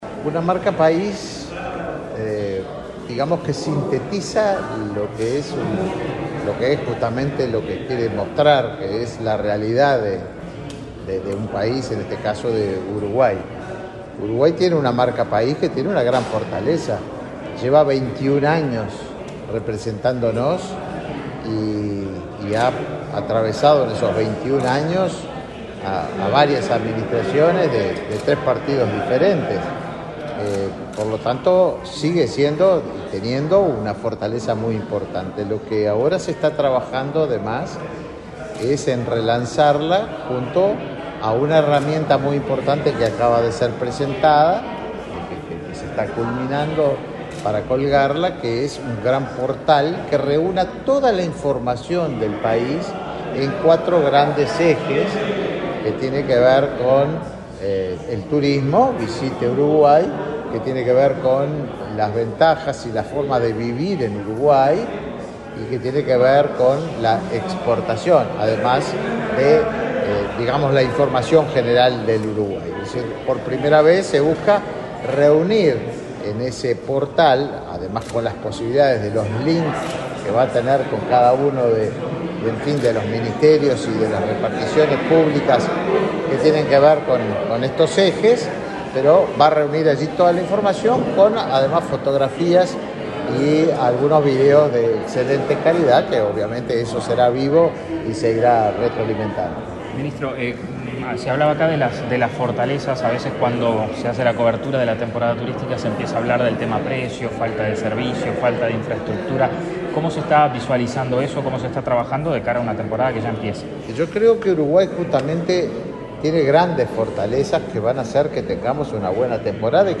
Declaraciones a la prensa del ministro de Turismo, Tabaré Viera
Declaraciones a la prensa del ministro de Turismo, Tabaré Viera 27/10/2022 Compartir Facebook X Copiar enlace WhatsApp LinkedIn Uruguay es sede de la novena edición del Foro Iberoamericano de Marca País. Tras la apertura del evento, este 27 de octubre, el ministro de Turismo realizó declaraciones a la prensa.